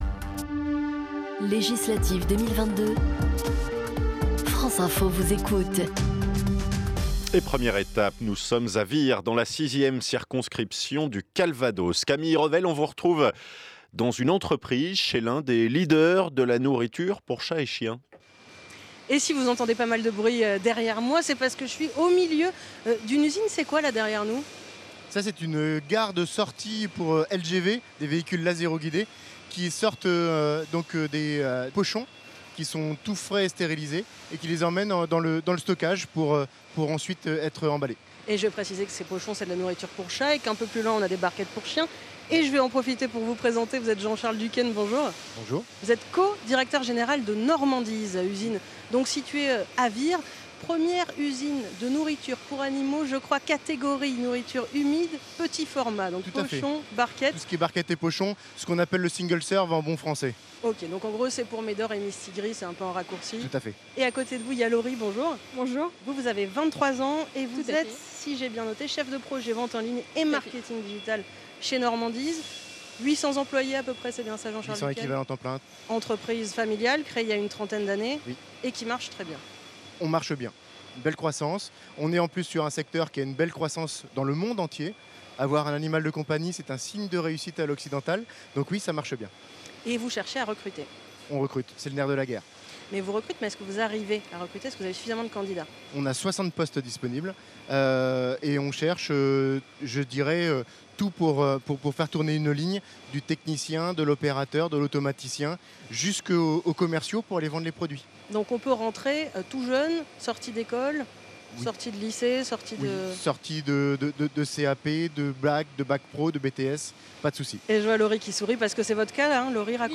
La Normandise recrute. Itws